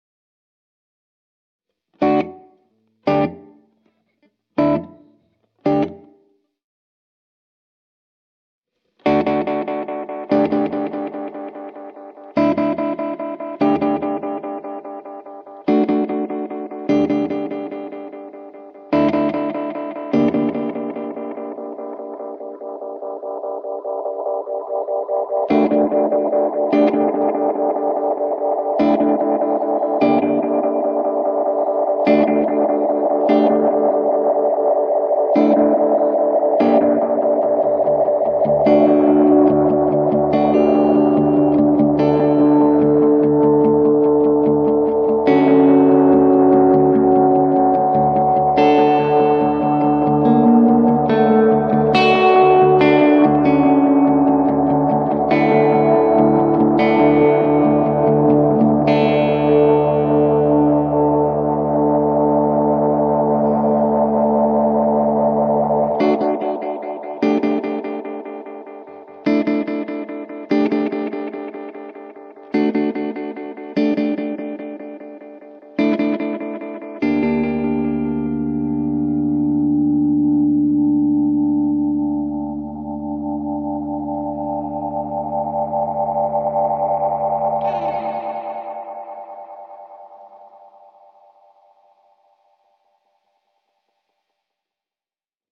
Utilizing an expression pedal, lengthy percussive repeats move back and forth between cleaner tape sounds and dark, murky, self-oscillating tones.